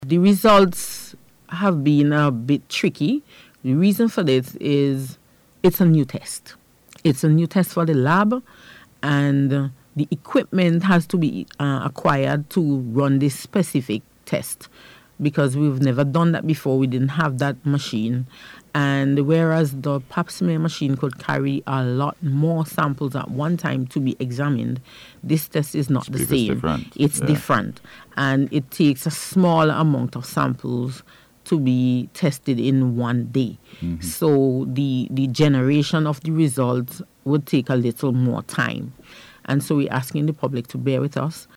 Speaking on radio recently